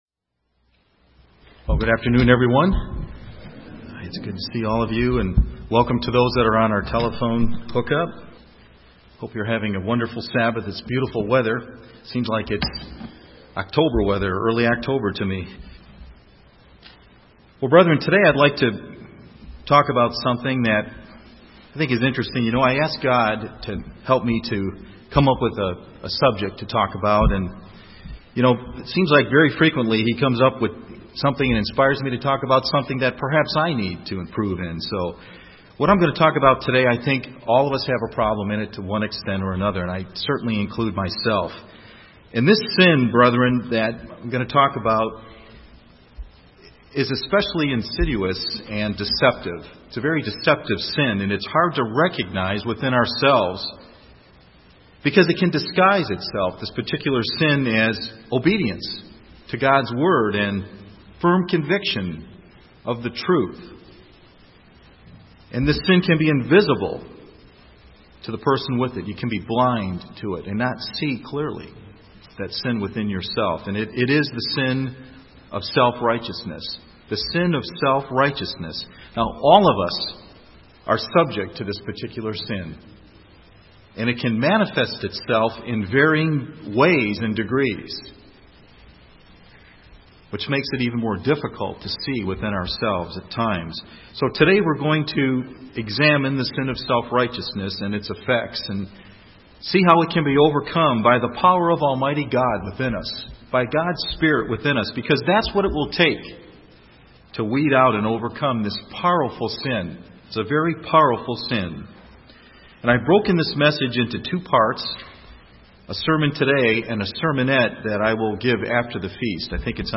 (Part 1 of 2) UCG Sermon Studying the bible?